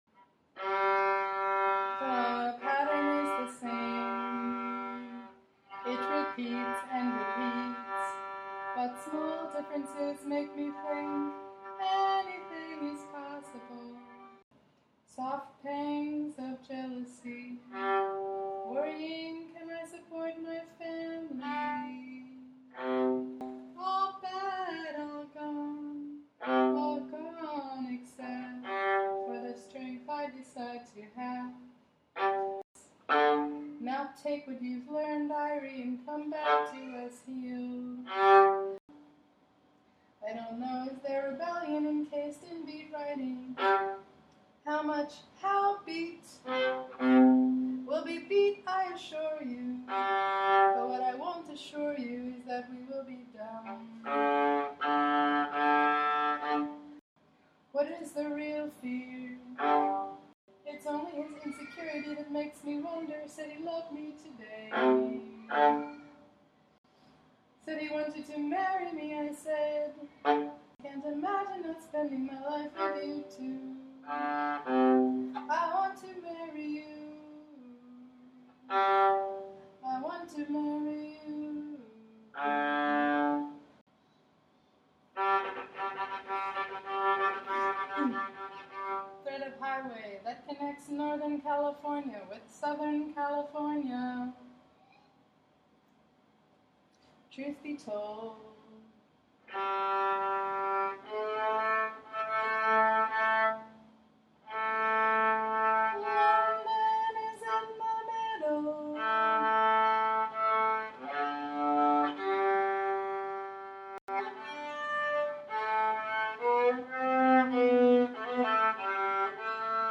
mvt. 1 mvt. 2, score of mvt. 3– three movement symphony for voice, viola, ukulele, clarinet, trumpet trombone, and percussion. 2018